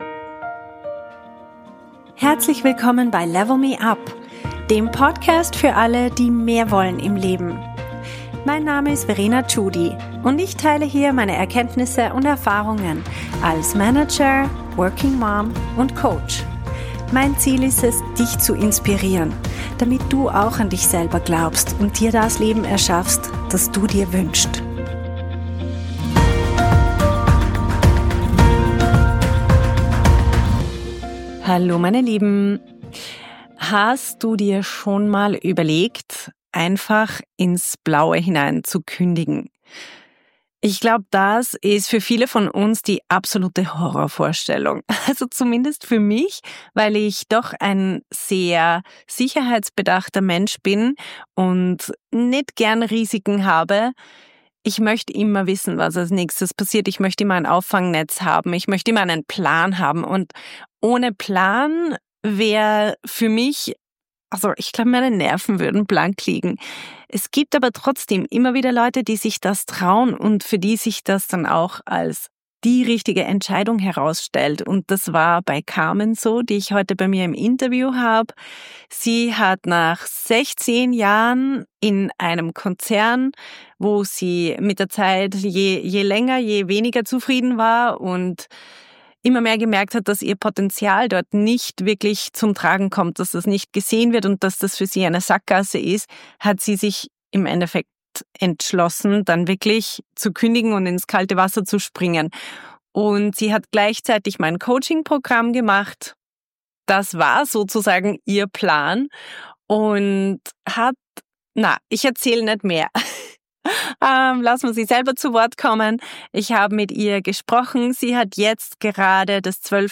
Sprung ins kalte Wasser - Interview